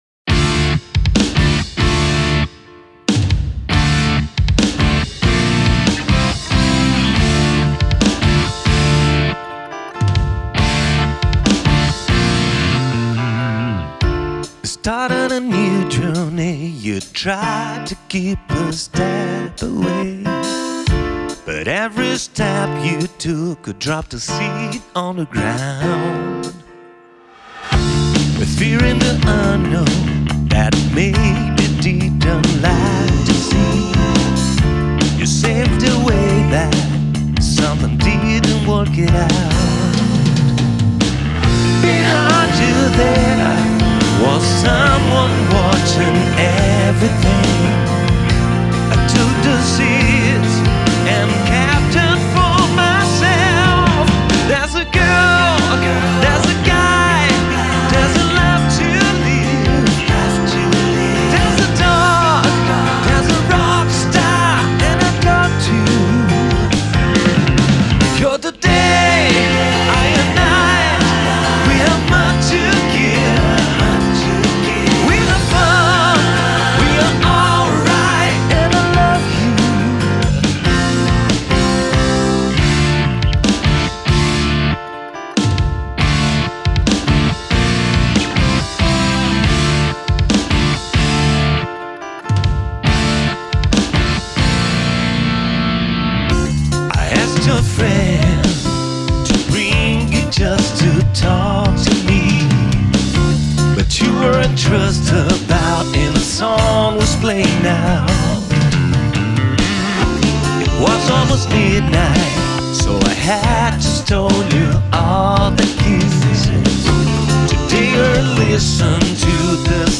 pre-production
vox, acostic + electric guitars, keys
drums
guitars + solo 7th song